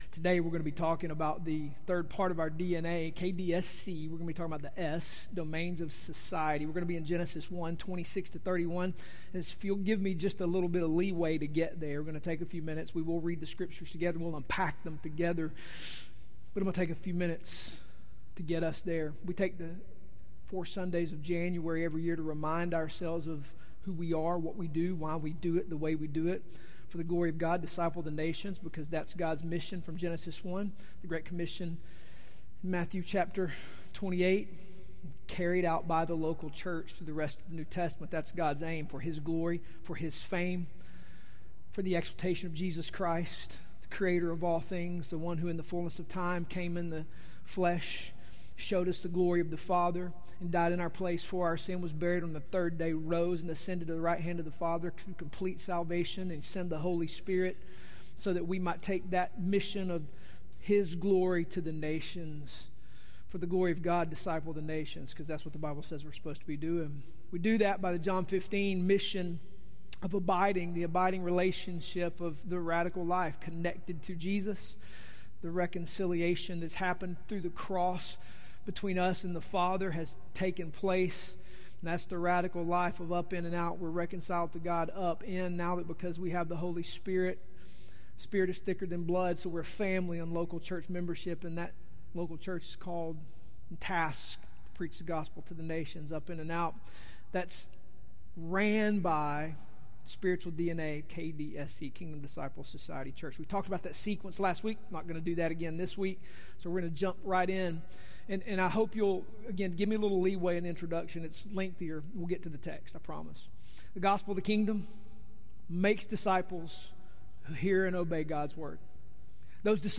Today we are on our 3rd of 4 sermons breaking down our Kingdom DNA as a church, which is KDSC. Today we talk about Society.